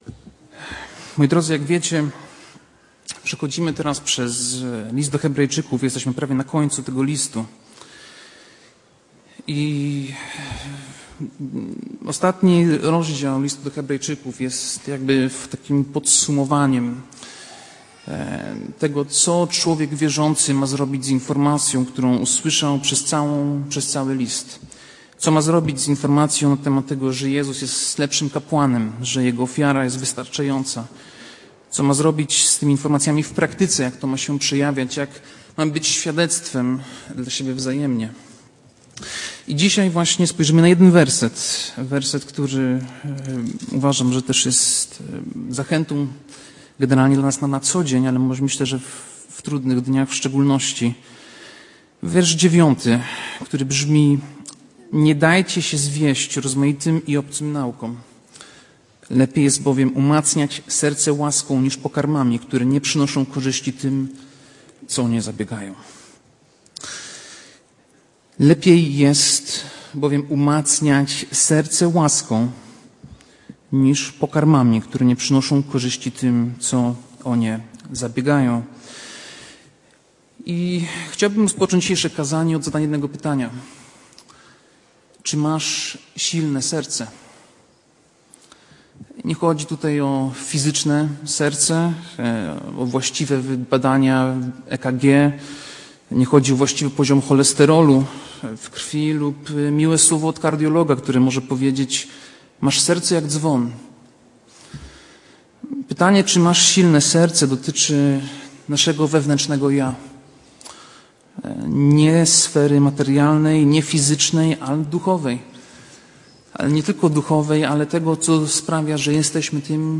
Kazanie
wygłoszone na nabożeństwie w niedzielę 23 listopada 2025 r. Tematy: ewangelia , łaska , modlitwa , wieczerza Pańska